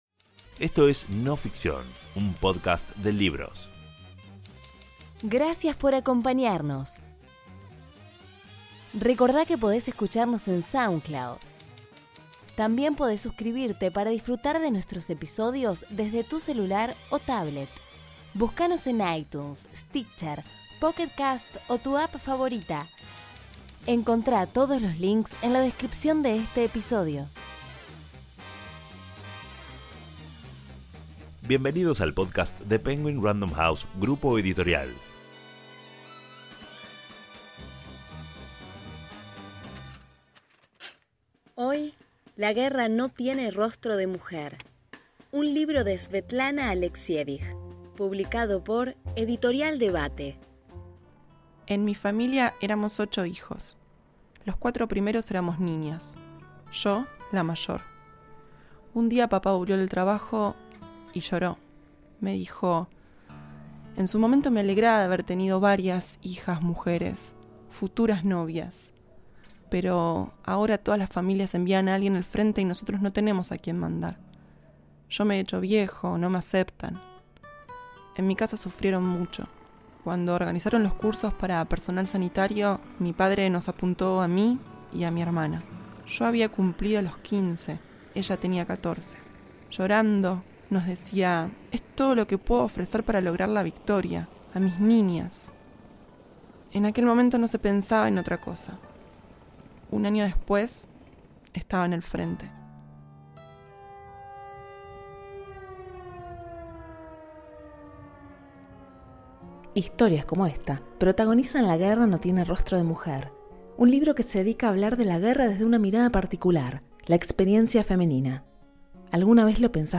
Actuación
Narradora